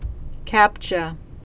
(kăpchə) Computers